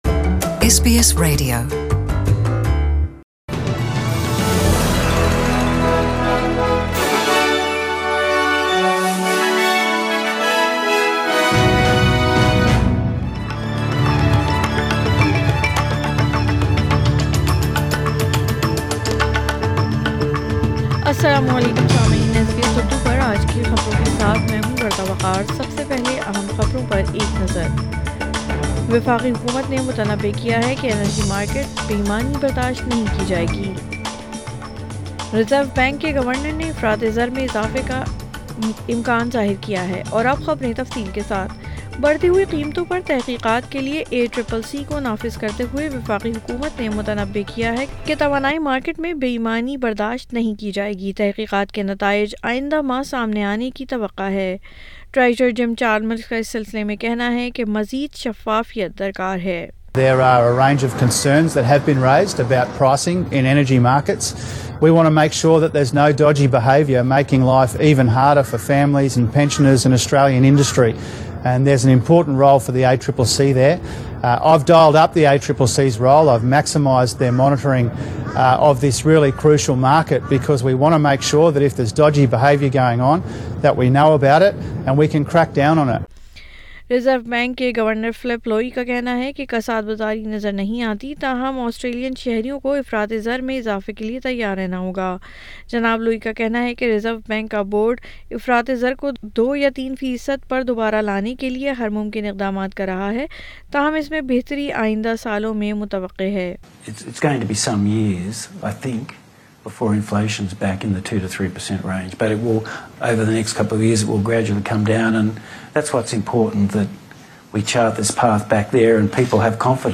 SBS Urdu News 21 June 2022